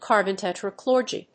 アクセントcárbon tetrachlóride